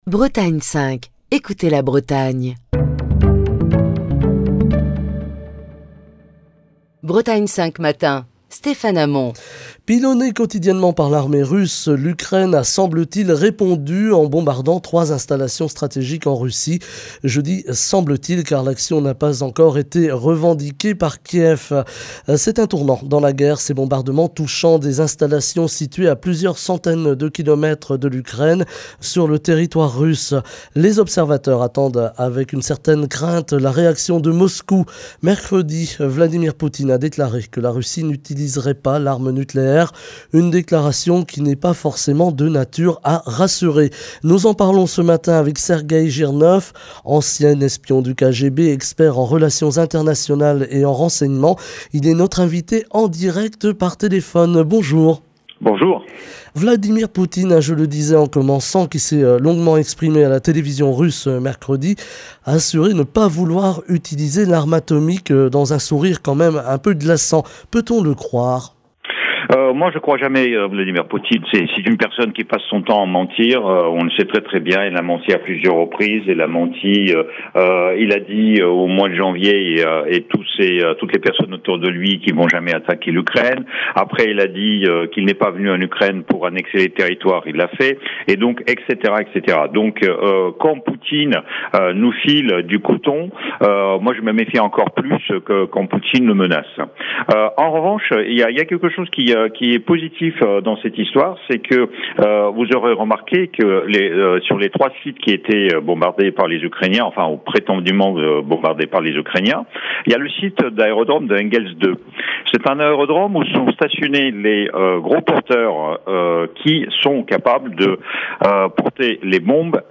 Sergueï Jirnov, ancien espion du KGB, expert en relations internationales et en renseignement, est notre invité ce vendredi.